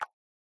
chat-plop.mp3